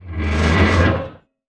metal_scrape_deep_grind_squeak_02.wav